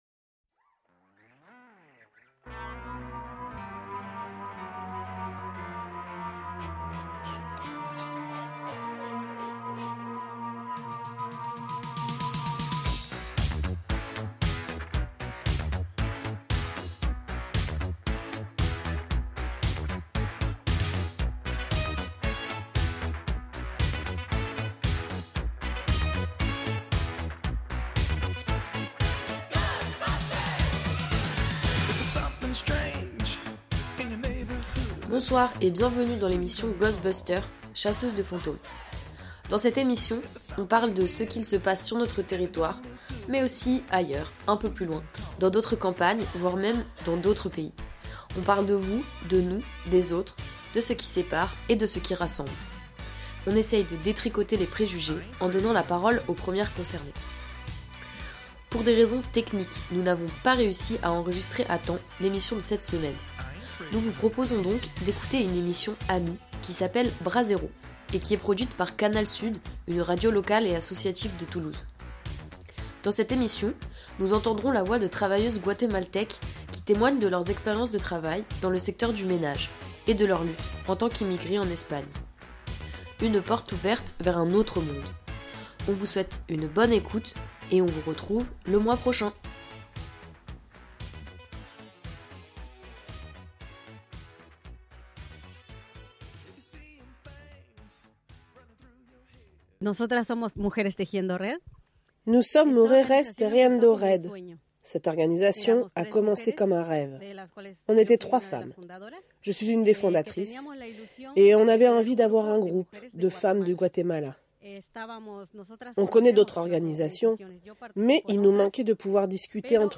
Nous vous proposons donc d’écouter une émission « amie », Brasero, produite par Canal Sud, une radio locale et associative de Toulouse. Dans cette émission, nous entendrons la voix de travailleuse Guatémaltèques qui témoignent de leur expérience de travail dans le secteur du ménage et de leurs luttes en tant qu’immigrées en Espagne.
Invité(s) : l’équipe de Brasero, les travailleuses Guatémaltèques rencontrées au festival La Fa Mi